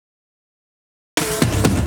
Fill 128 BPM (28).wav